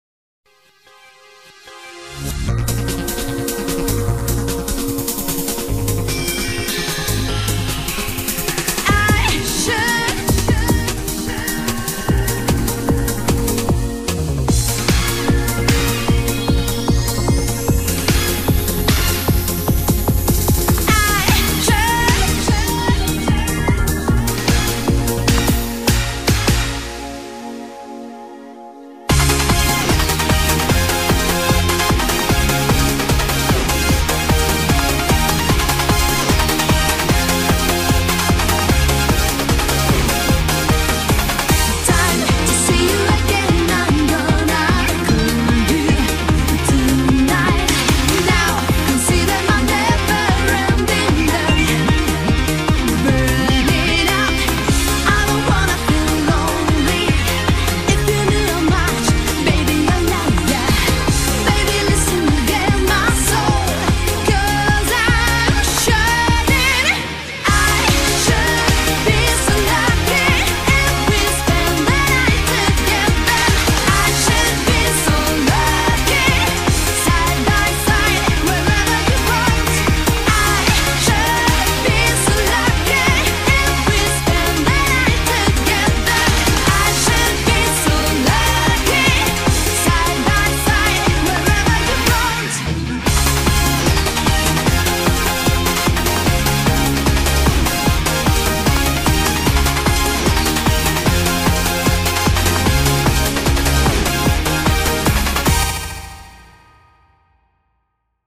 BPM75-150